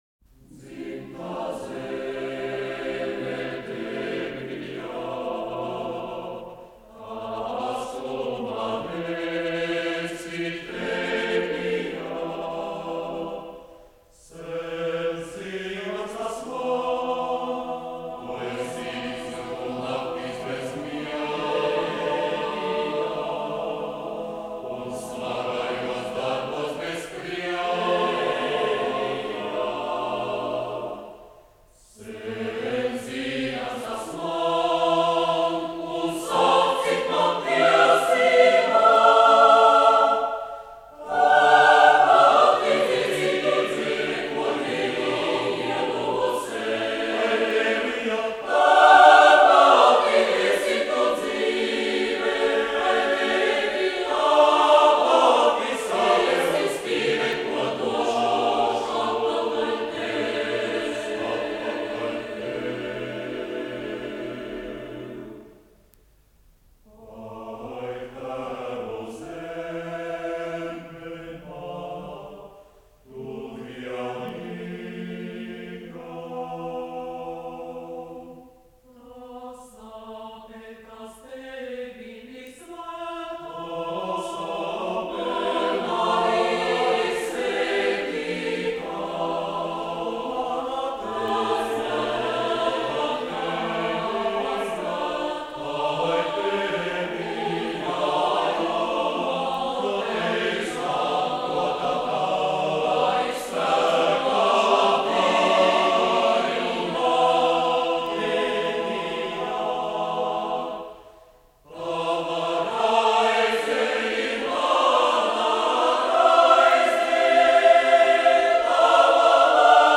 Kora mūzika -- Latvija
Mono kopija F-27199
Mūzikas ieraksts